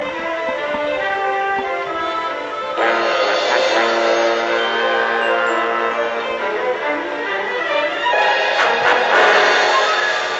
Dramatic type music